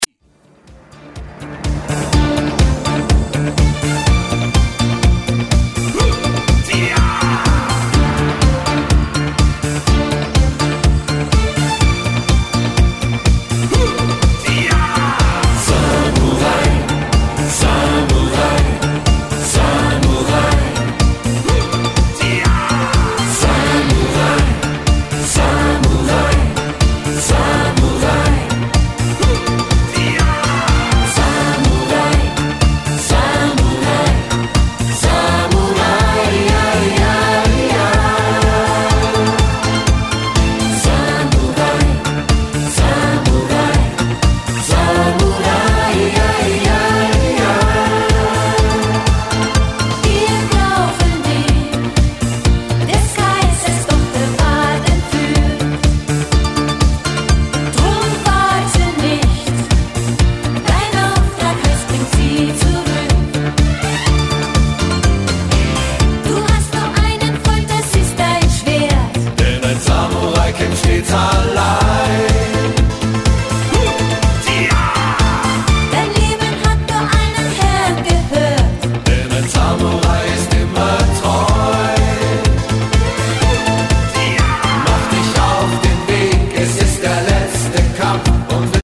Disco-Pop